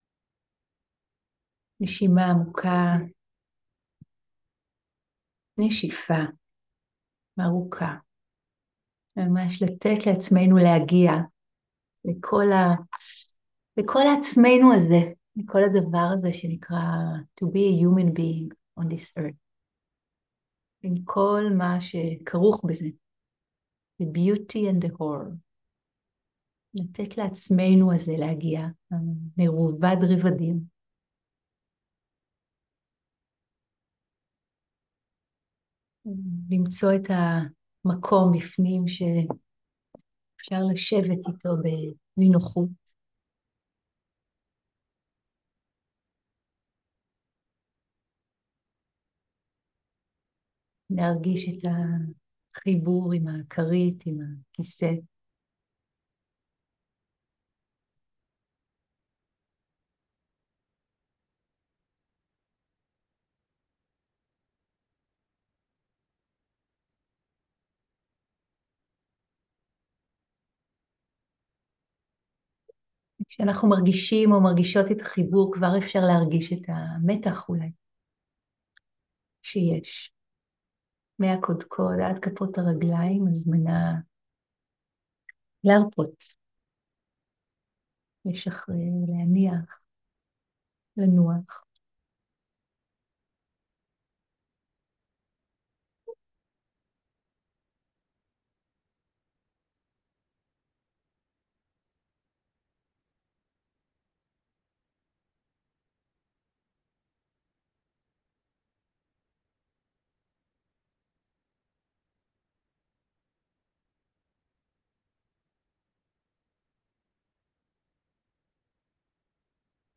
07.11.2023 - מרחב בטוח - מדיטציה (חלק א) - לתת לעצמנו להגיע ולנוח ברגע הזה